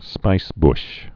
(spīsbsh)